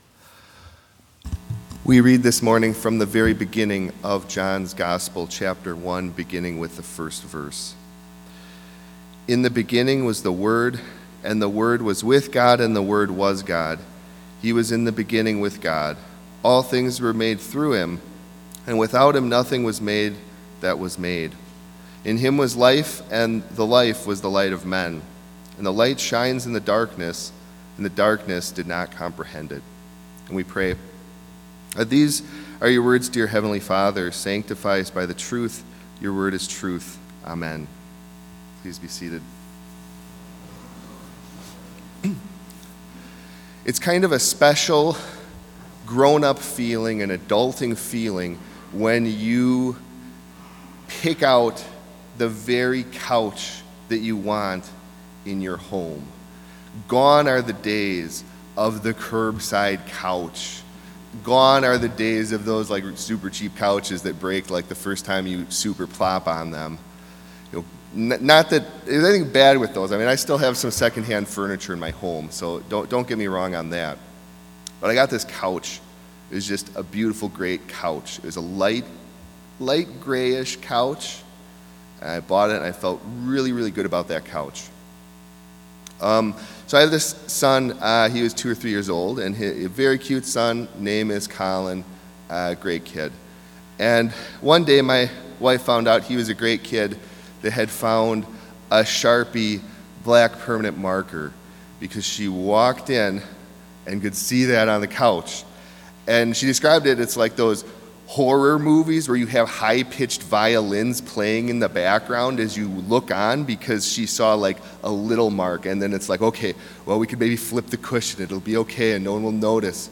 Complete service audio for Chapel - December 2, 2021